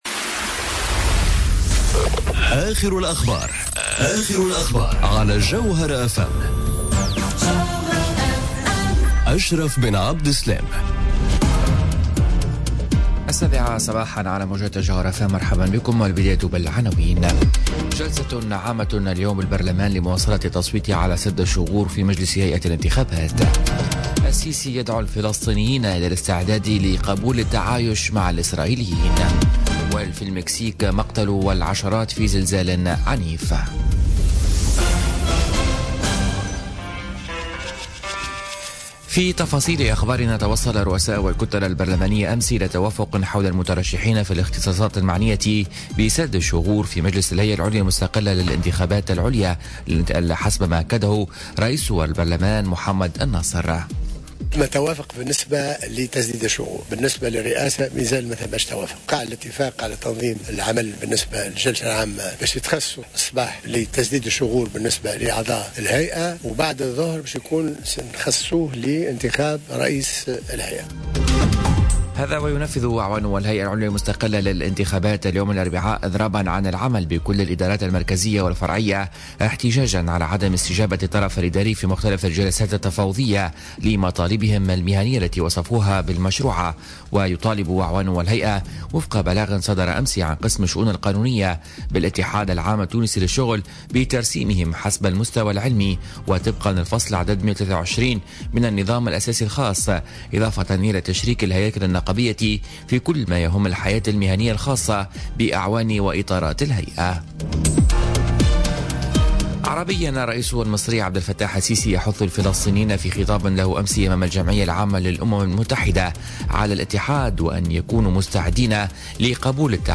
-نشرة أخبار السابعة صباحا ليوم الإربعاء 20 سبتمبر 2017